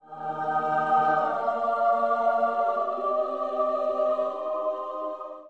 Revive_SFX.wav